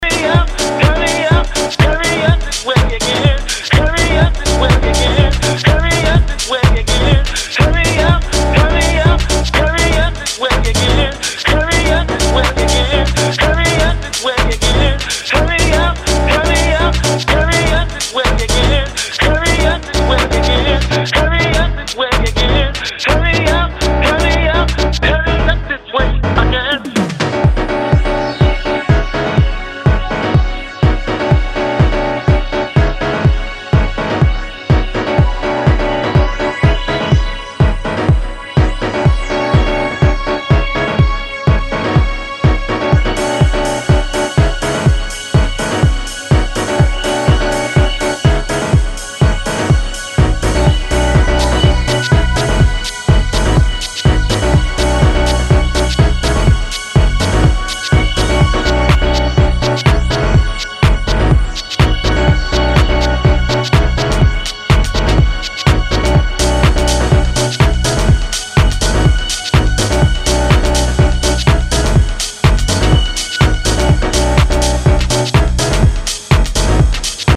packed with soul and delivered with a weighty bottom end